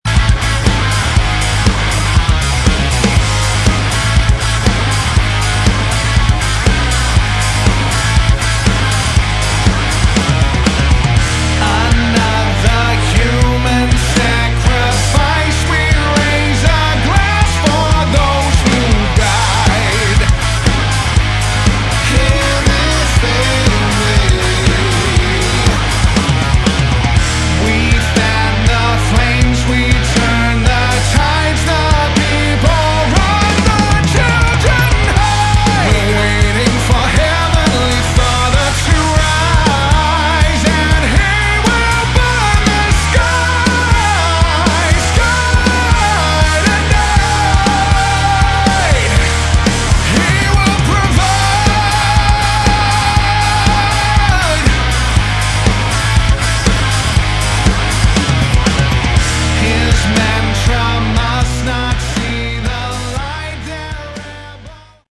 Category: Hard Rock
Lead Vocals
Guitar
Bass Guitar
Drums, Percussion
keys, piano